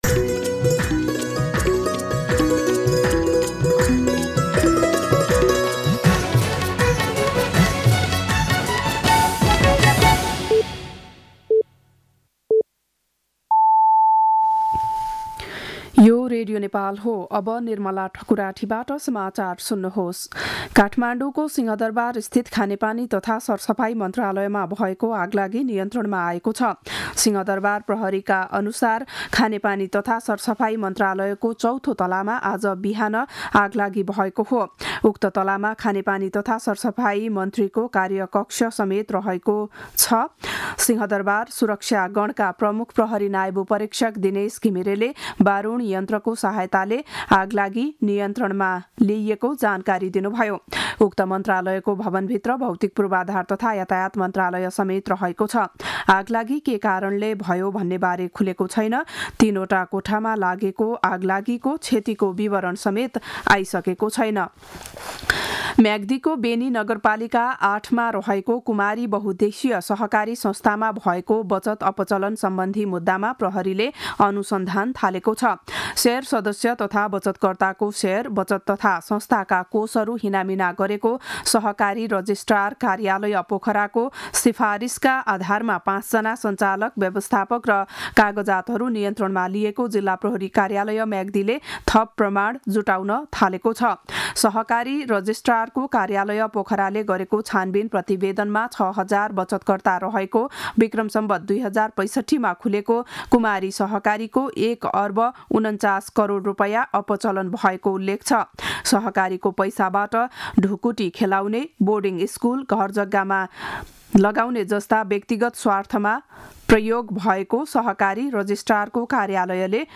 बिहान ११ बजेको नेपाली समाचार : २७ माघ , २०८१